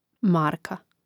màrka marka